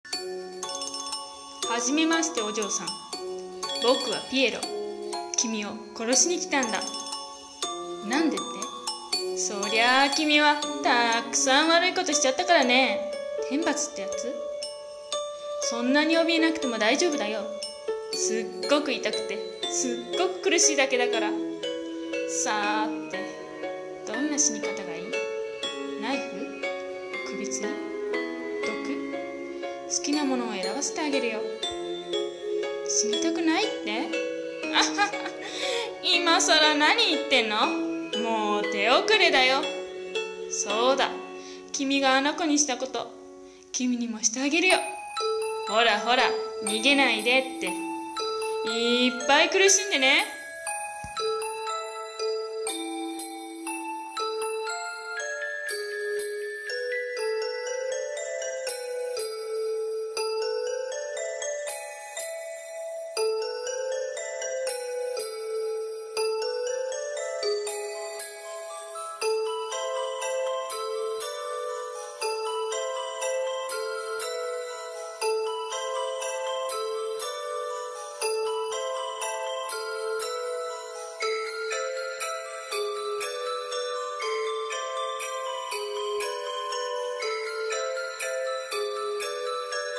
【声劇】道化師